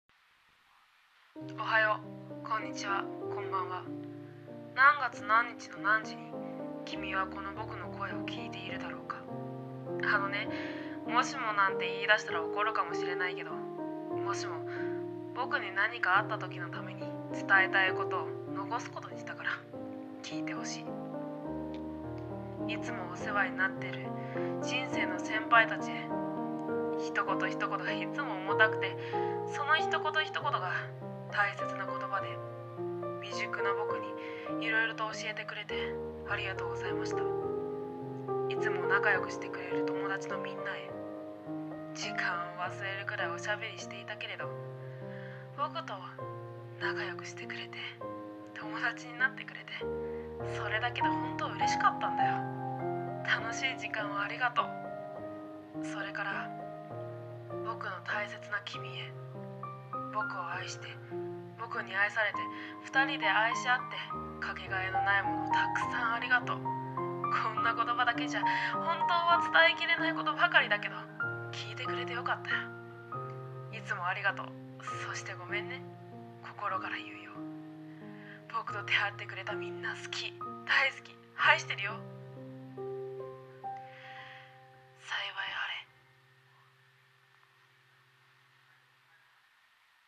一人声劇】幸いあれ